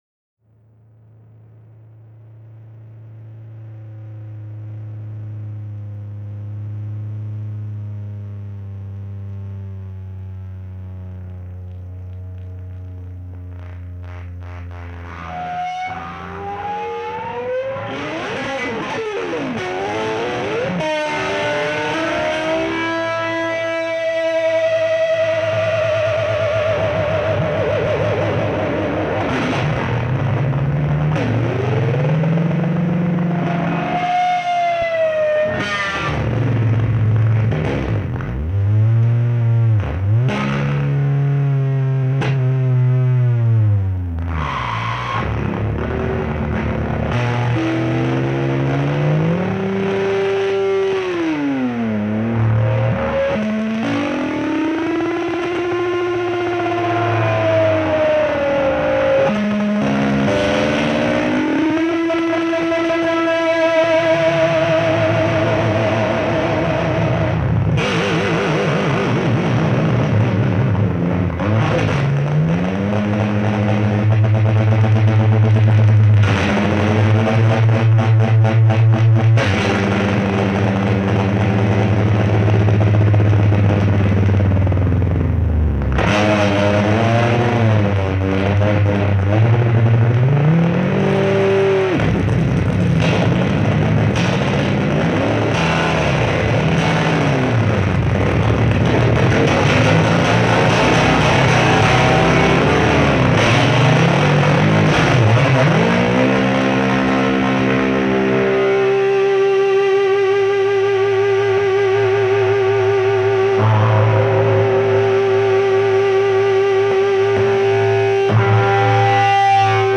Жанр: Jazz-Rock